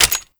GunEmpty.wav